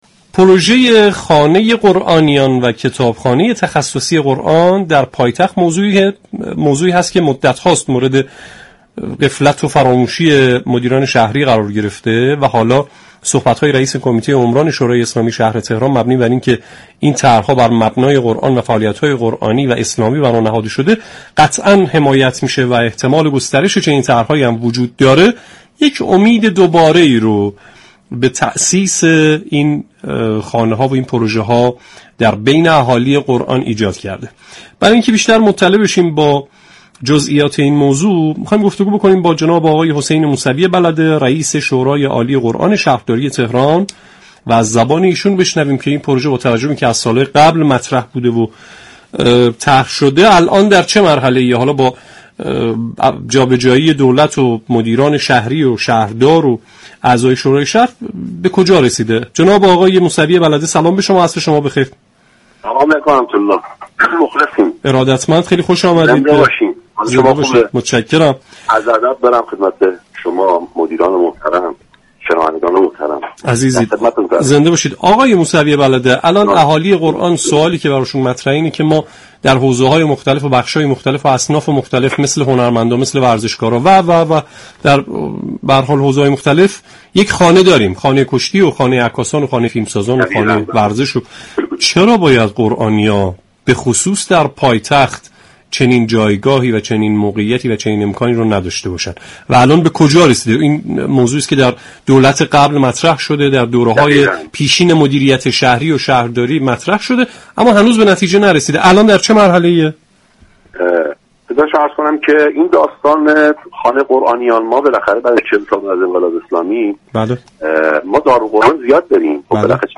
در گفت‌وگو با برنامه والعصر رادیوقرآن درباره بلاتكلیفی تأسیس خانه قرآنیان و كتابخانه تخصصی قرآن در پایتخت گفت